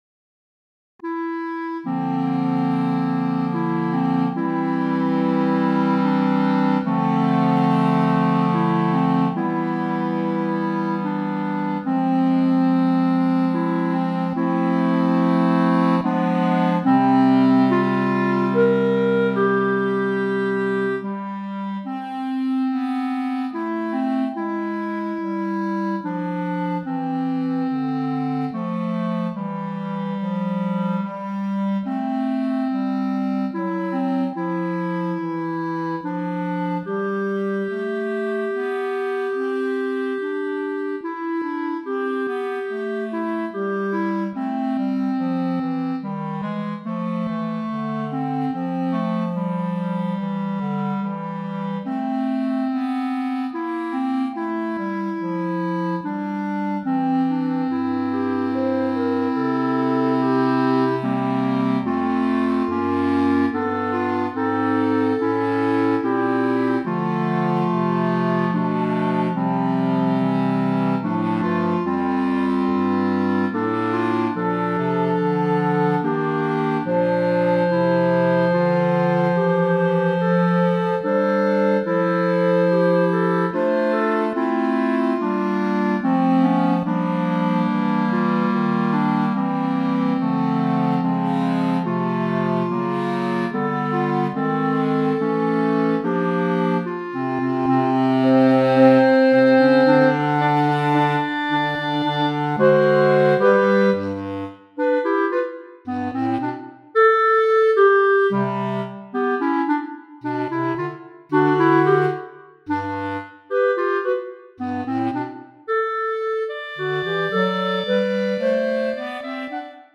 Voicing: Clarinet Quartet